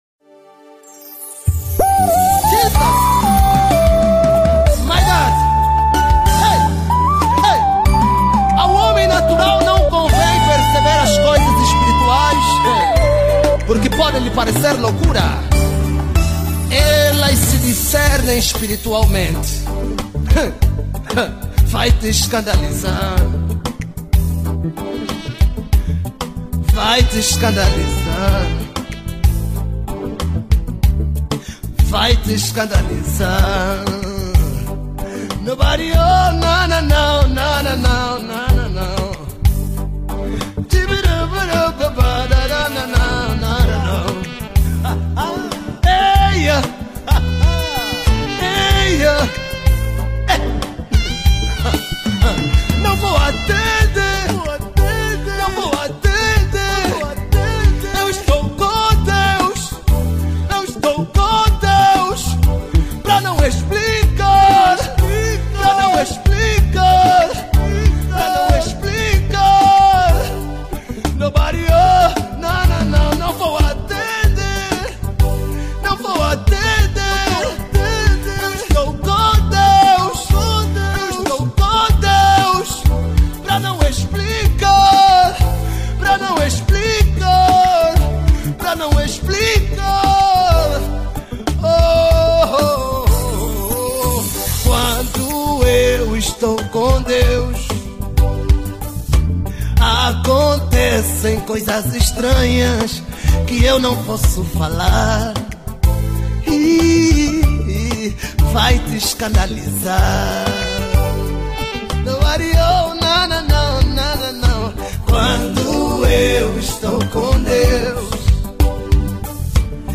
Gospel 2021